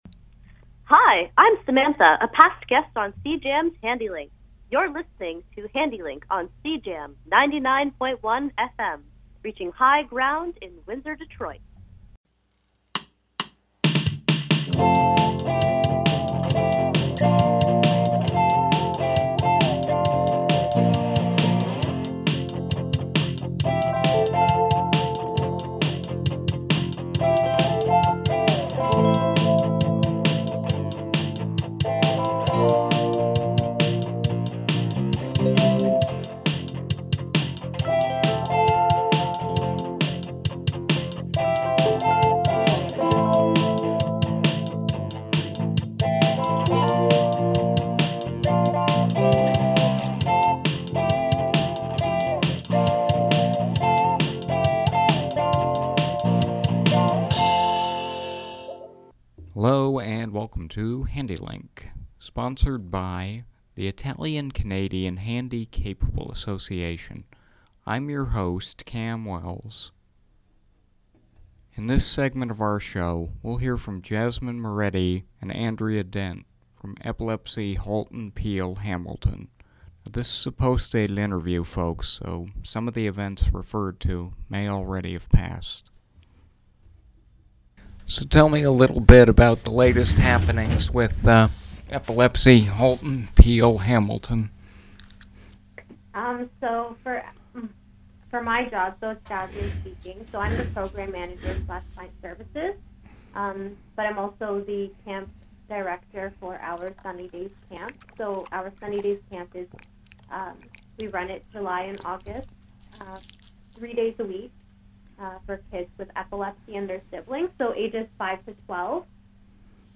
Handi-Link-_Epilepsy_panel_.mp3